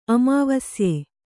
♪ amāvasye